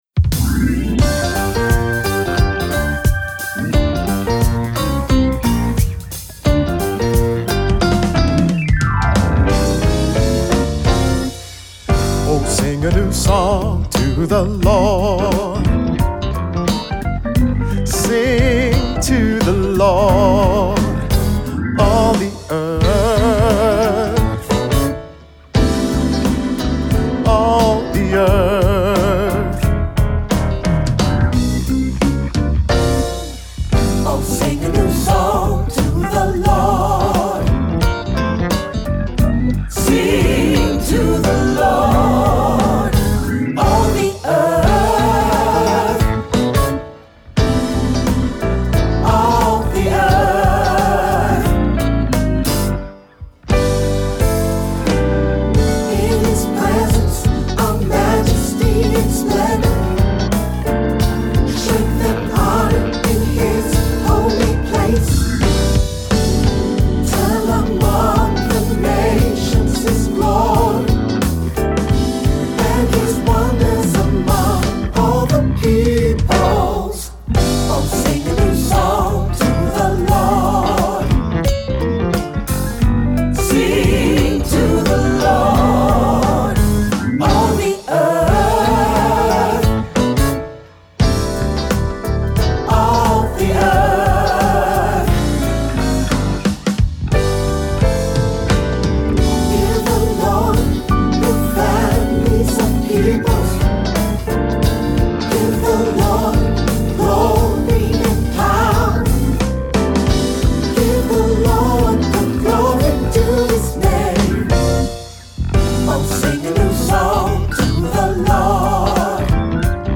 Voicing: SATB; Cantor; Solo; Assembly